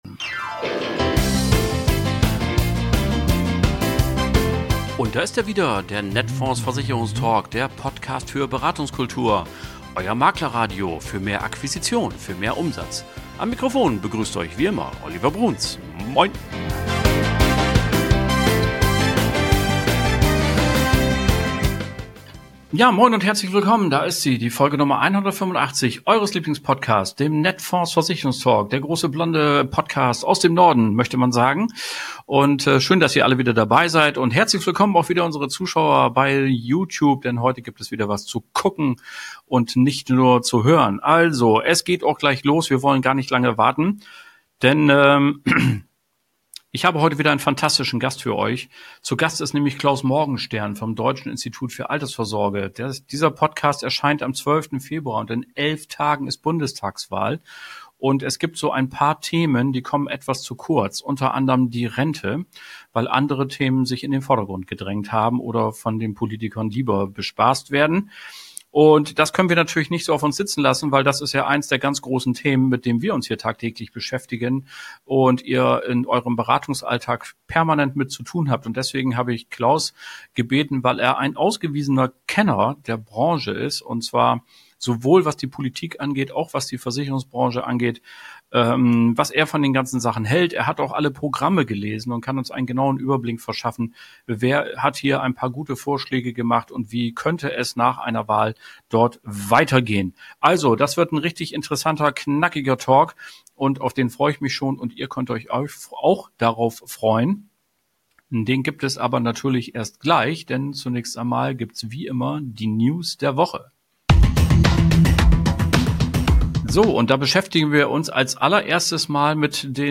Hören Sie spannende Interviews und Reportagen mit praktischen Tipps oder vertiefenden Hintergrund-Informationen.